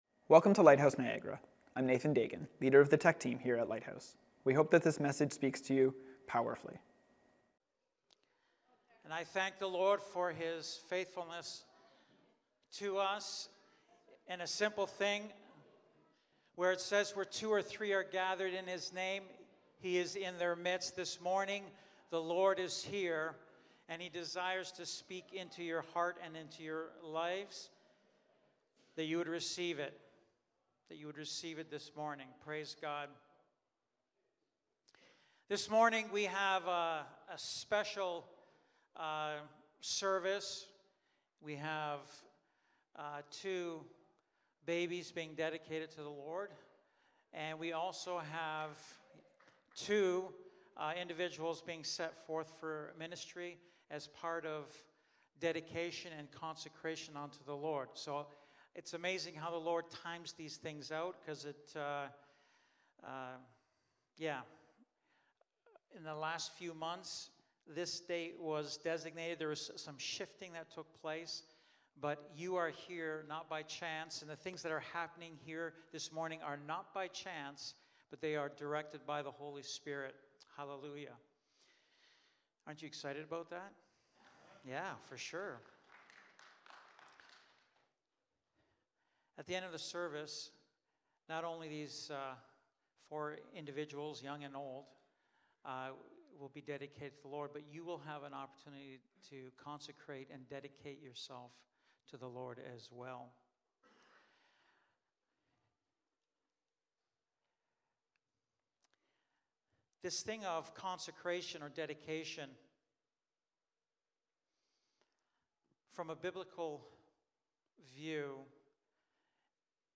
Lighthouse Niagara Sermons